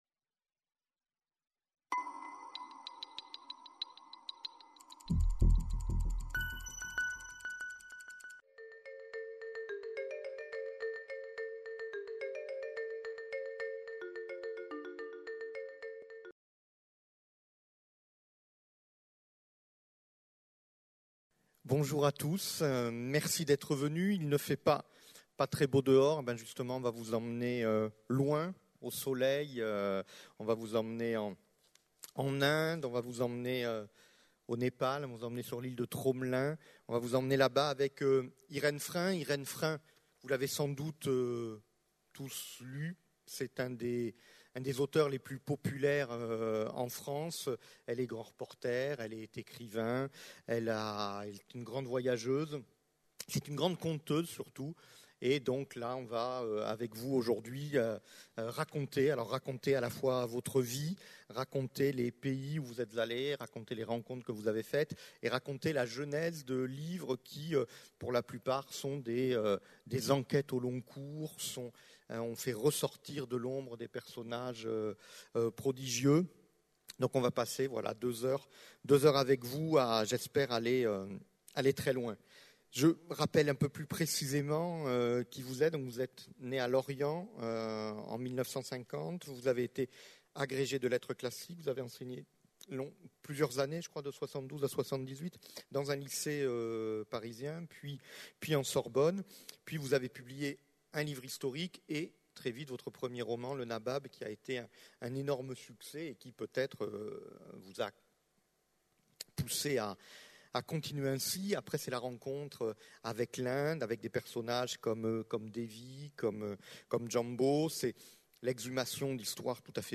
Conférence de l’Université populaire du quai Branly (UPQB), donnée le 20 janvier 2012.